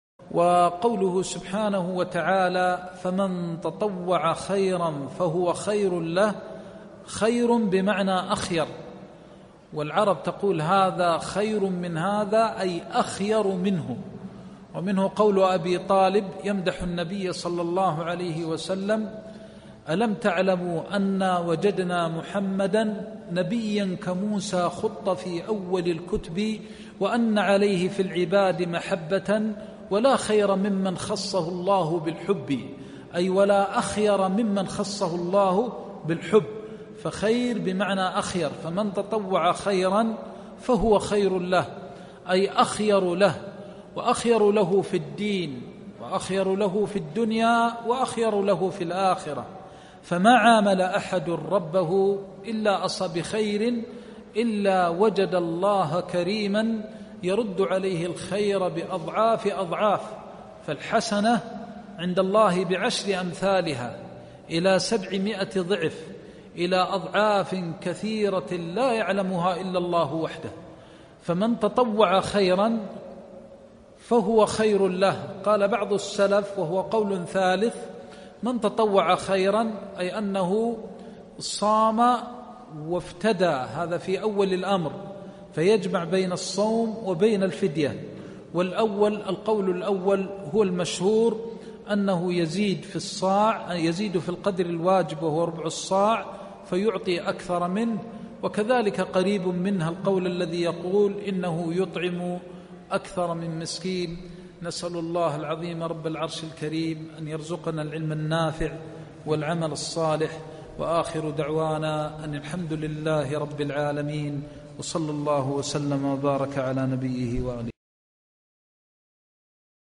دروس تفسير آيات الصيام(الحرم المدني)-درس (3)-قوله سبحانه(فمن تطوع خيرا فهو خير له)